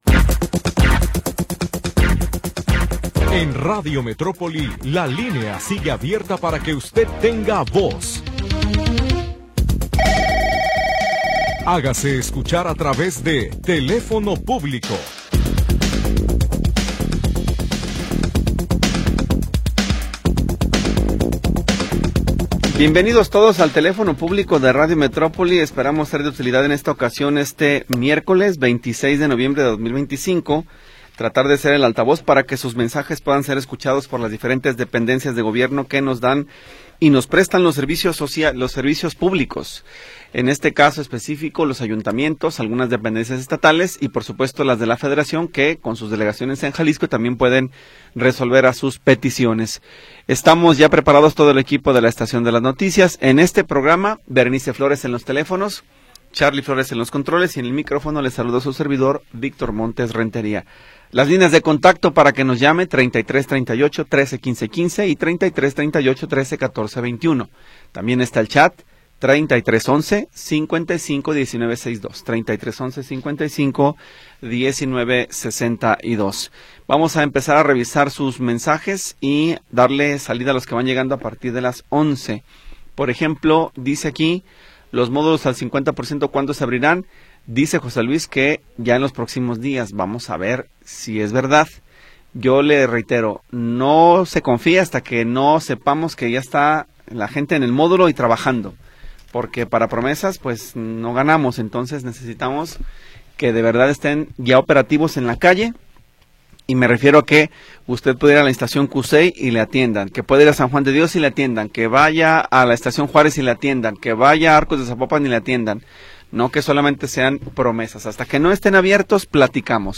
Programa transmitido el 26 de Noviembre de 2025.